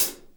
hat 1.wav